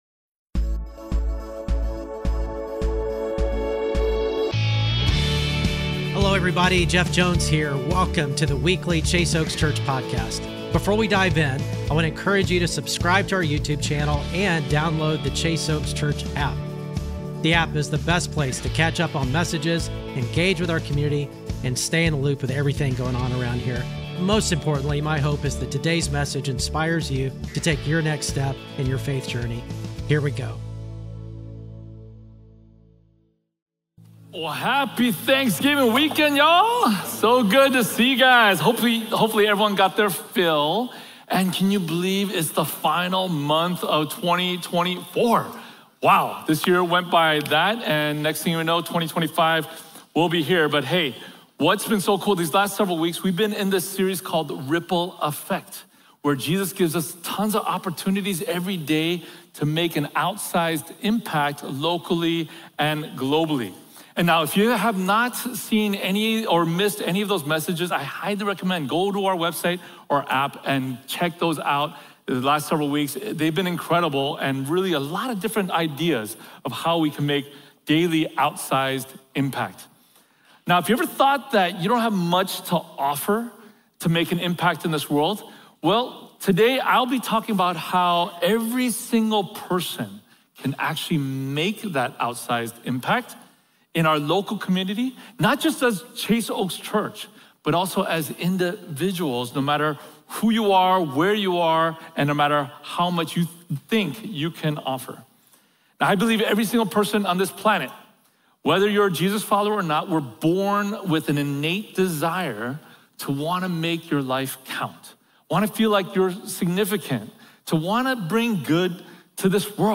Weekly Sermons at Chase Oaks Church in Plano, Texas